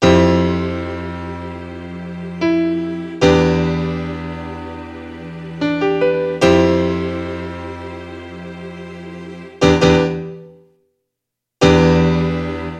描述：快速堵塞minilogue和tr606
声道立体声